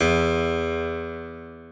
Harpsicord
e2.mp3